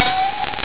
sound-ping.wav